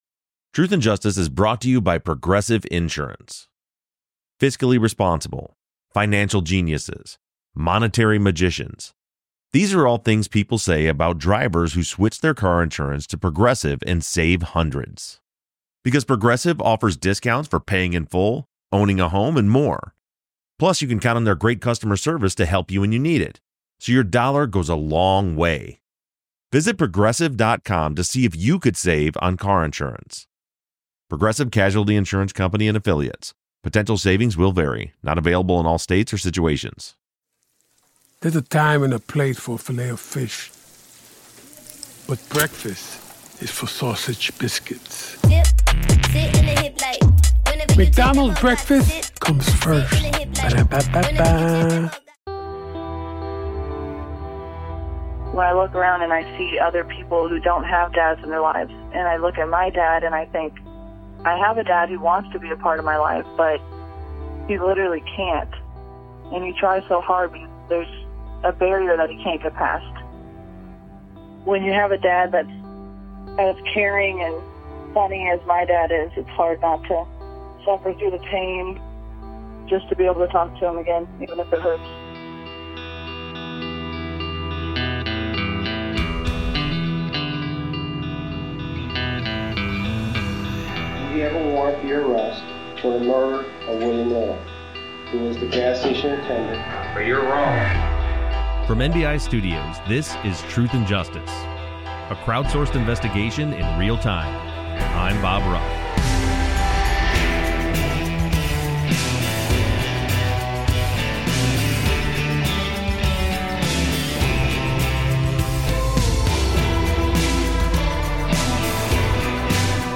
This week's episode features interviews